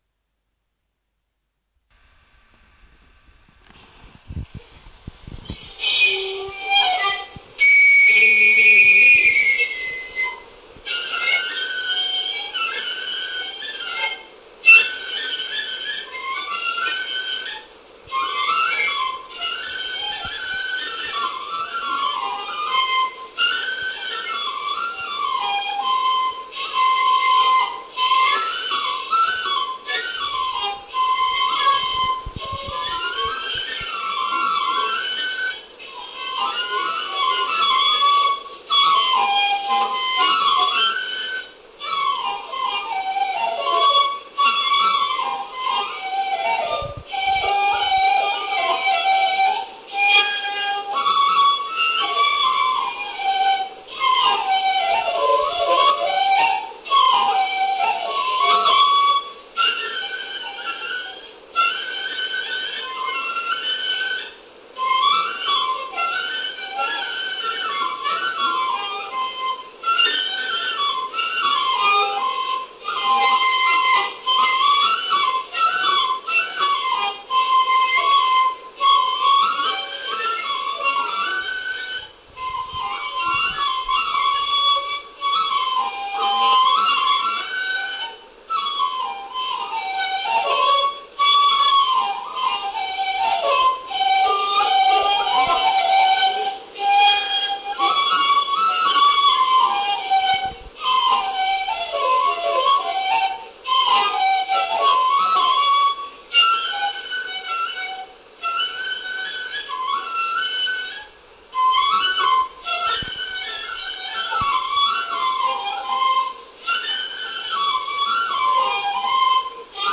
笛のメロディは借用したテープをそのまま再現しております。
笛のメロディ